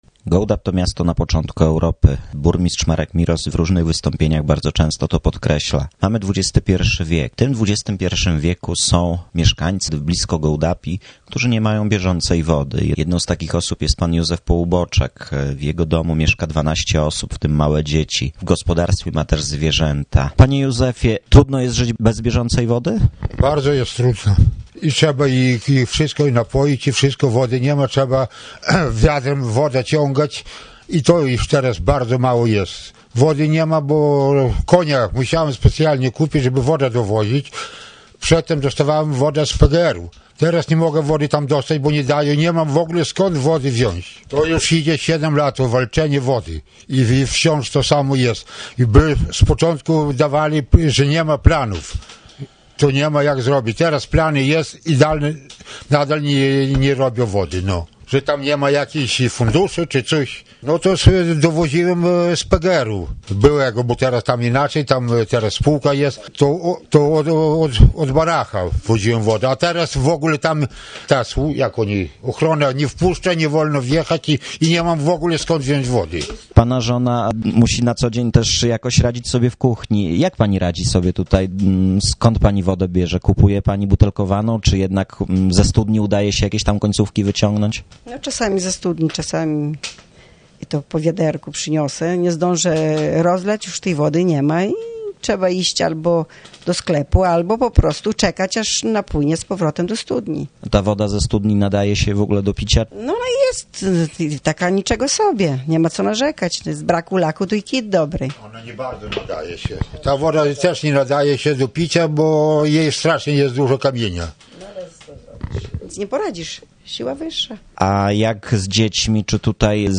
reportaż "Muszą się przyzwyczaić żyć jak zwierzęta?"